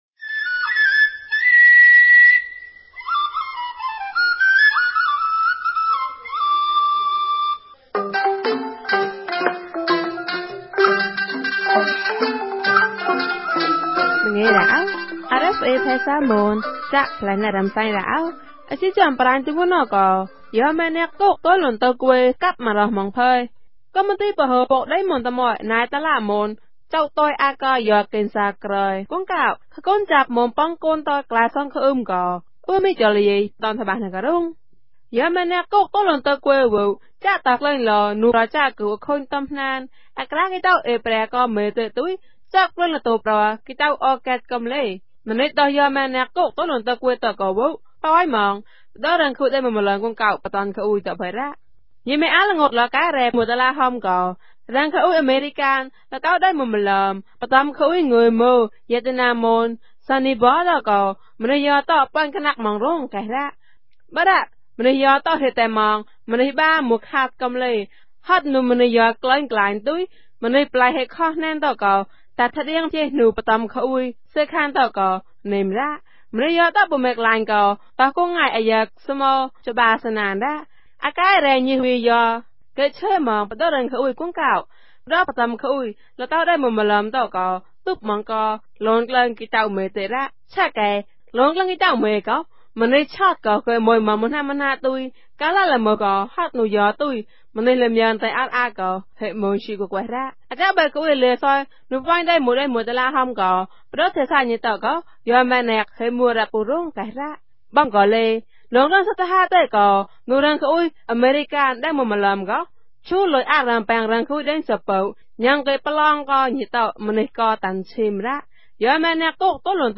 မြန်ဘာသာ အသံလြင့်အစီအစဉ်မဵား